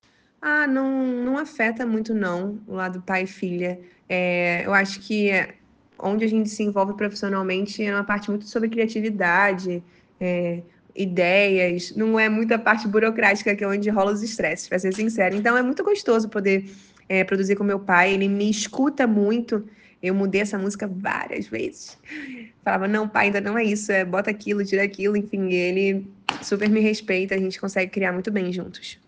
Resposta em áudio: